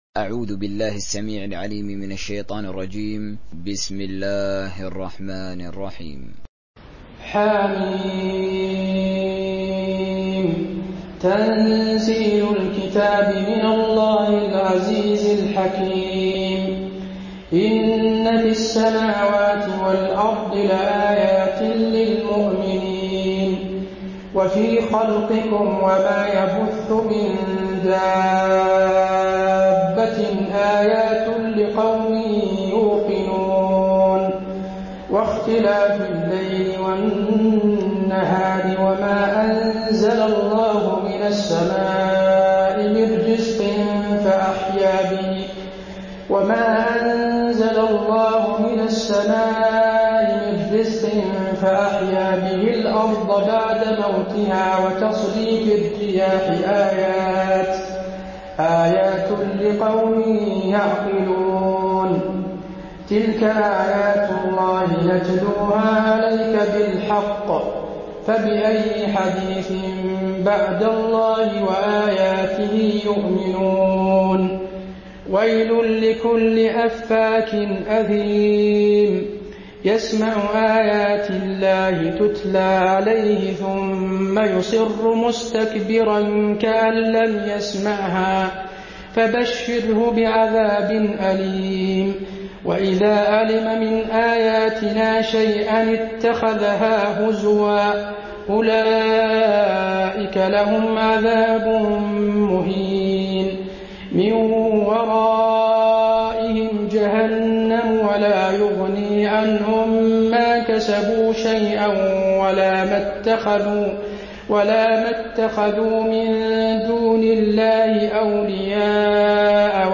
تراويح